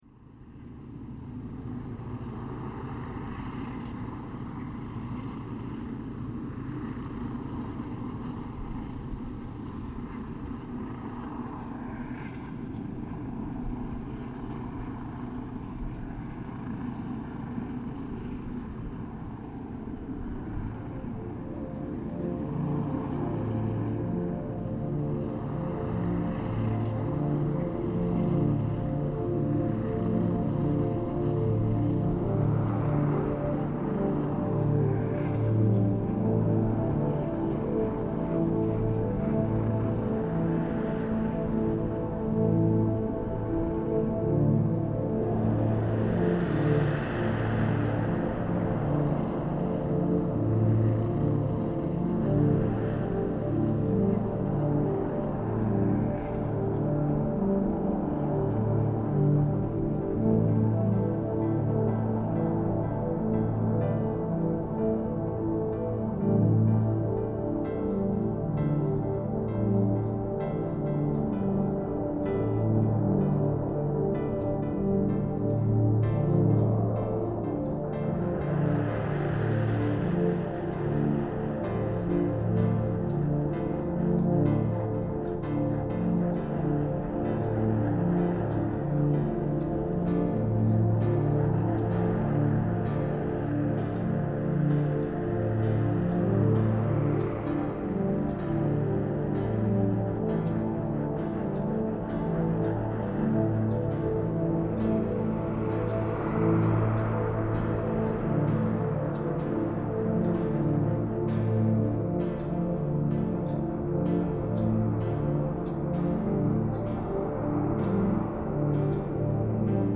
COP26 helicopter reimagined